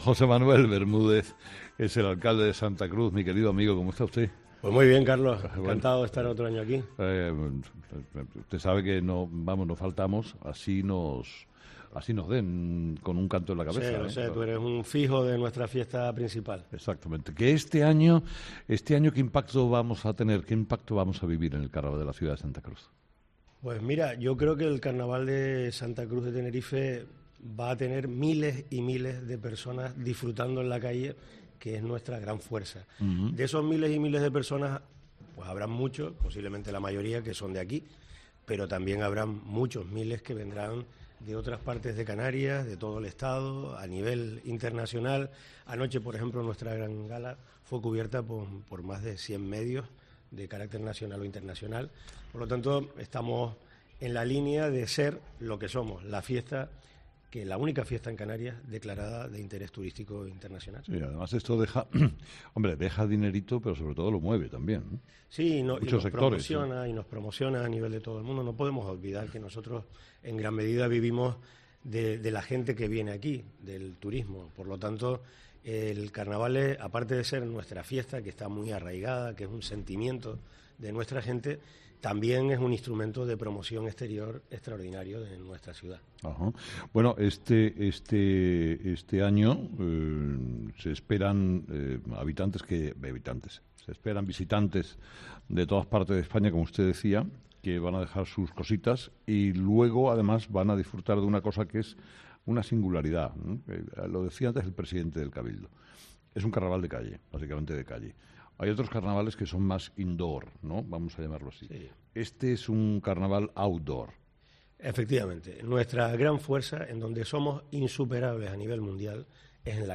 José Manuel Bermúdez, alcalde de Santa Cruz de Tenerife